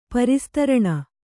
♪ pari staraṇa